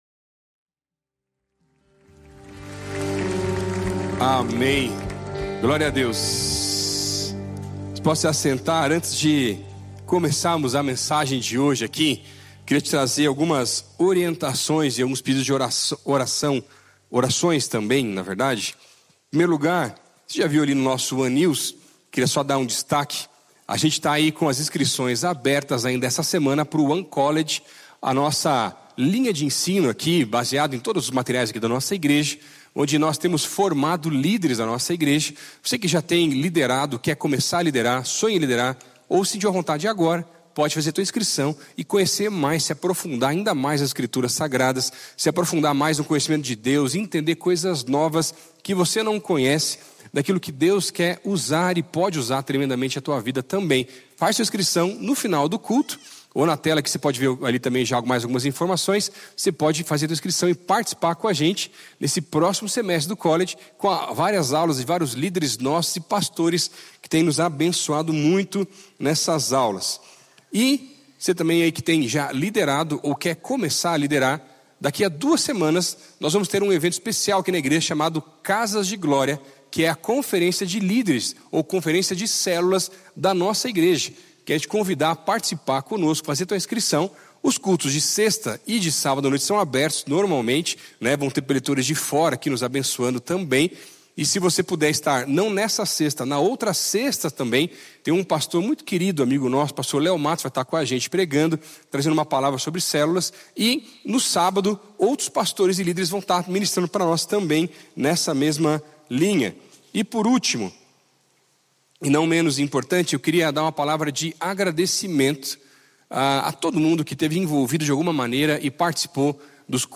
Culto ONE